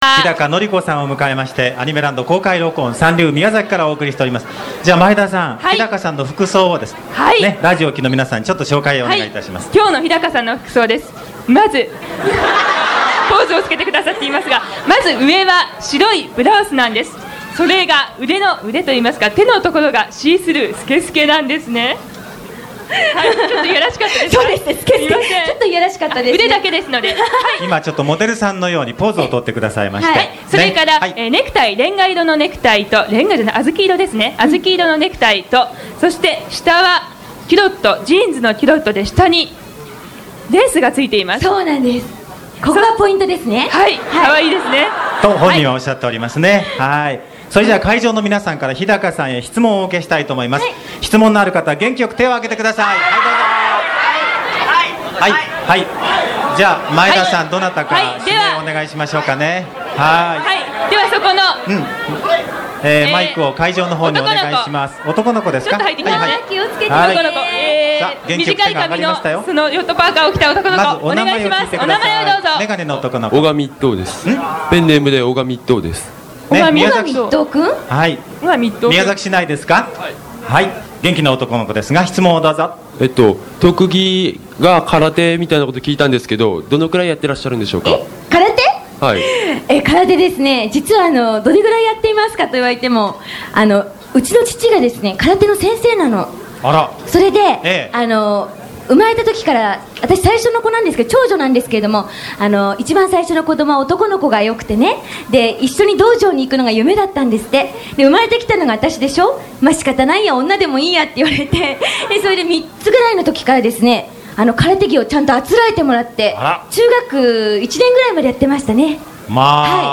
１９９１年５月　　　　サンリブ宮崎で日高のり子さんの公録が行われた。
１０００人をこえるファンのまえで声優の演じる美しくかわいいあかね、そして男の子の声、会場の皆はアッというまに一時間が過ぎた。